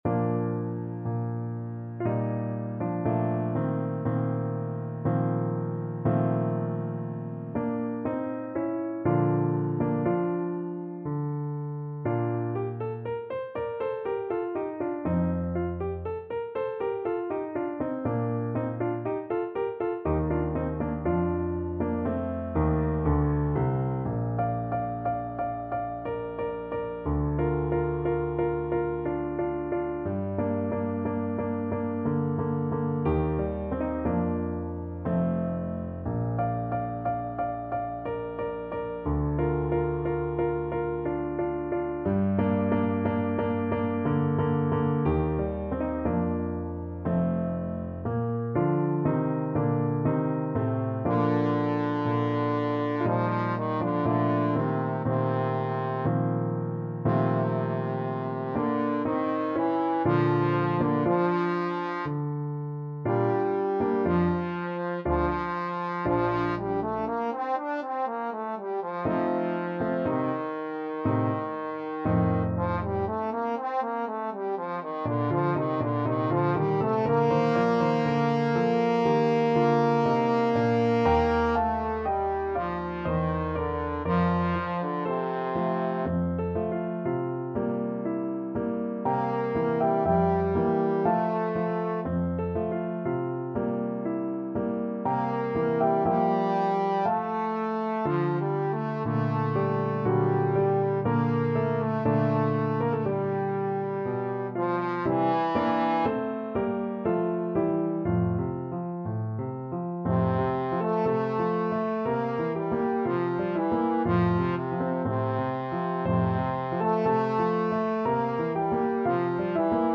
Trombone version
3/4 (View more 3/4 Music)
Classical (View more Classical Trombone Music)